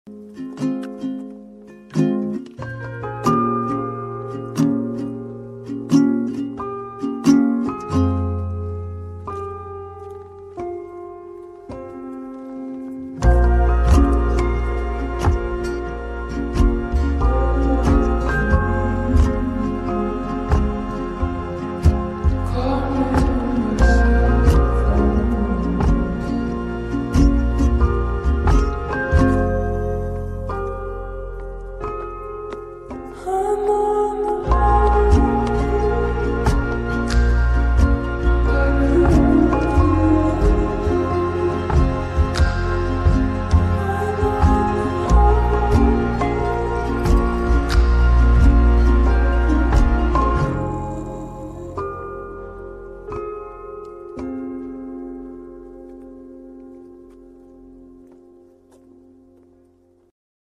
GTA pink weapon wheel console sound effects free download